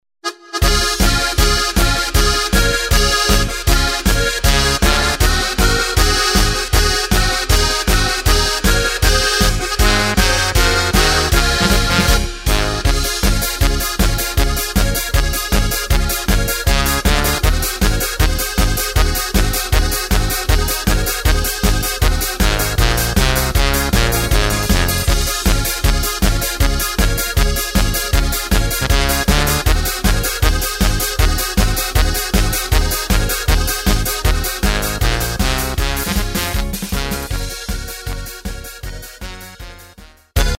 Takt:          2/4
Tempo:         157.00
Tonart:            C#
Flotte Polka aus dem Jahr 1990!
Playback mp3 Demo